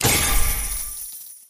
Appear_Scatter_Sound.mp3